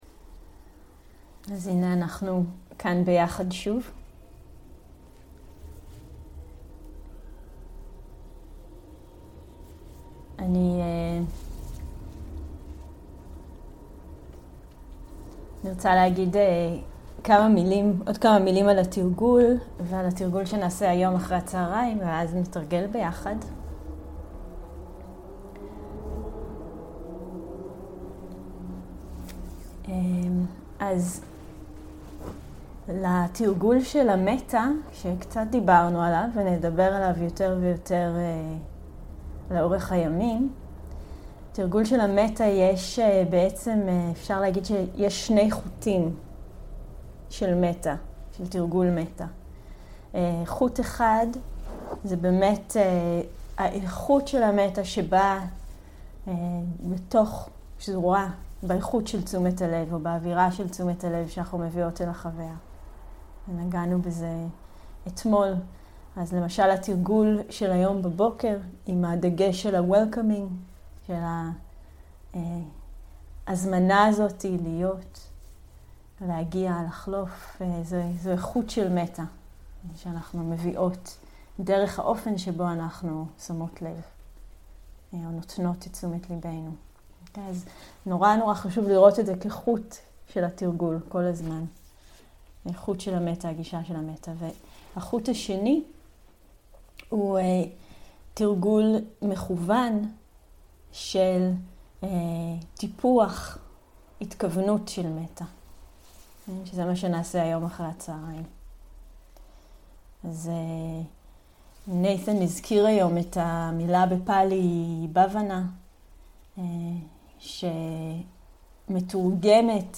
מדיטציה מונחית - מטא לעצמנו, לדמות קלה, ולעולם
סוג ההקלטה: מדיטציה מונחית
איכות ההקלטה: איכות גבוהה
ריטריט אונליין